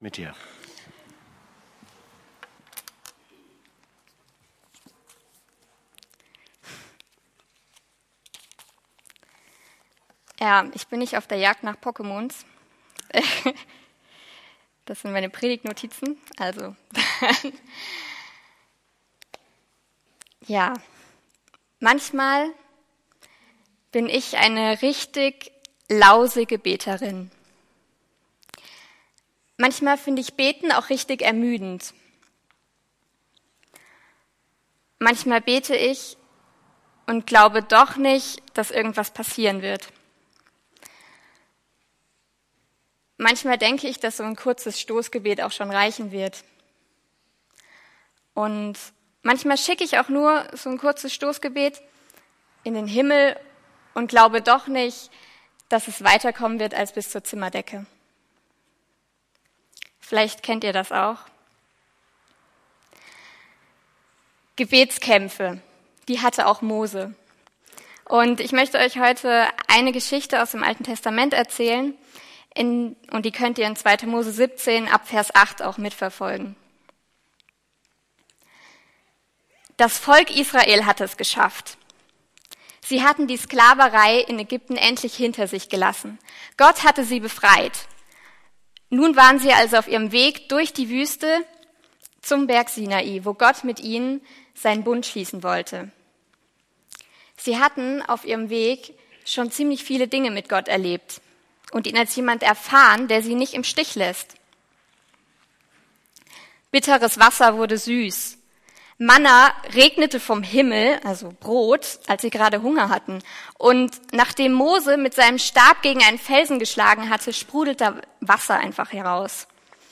Predigt
predigt.mp3